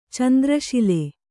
♪ candra śile